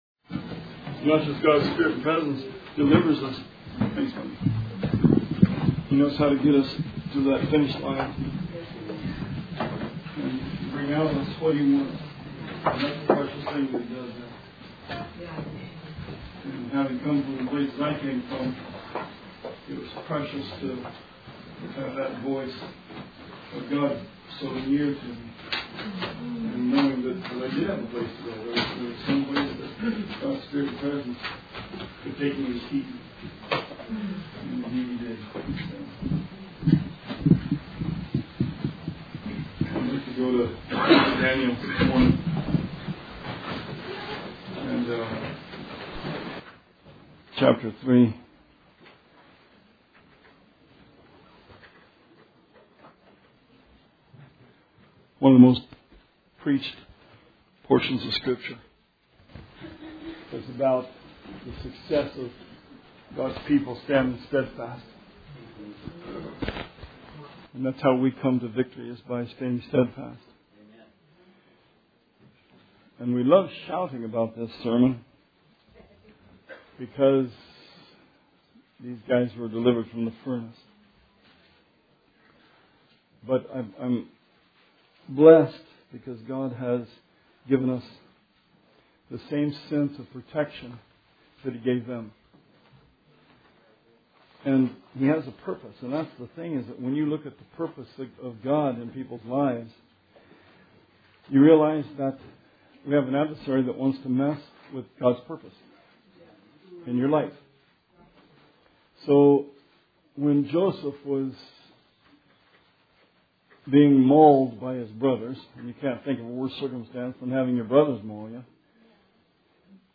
Sermon 5/15/16